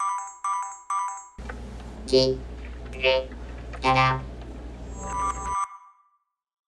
quota_announcement.wav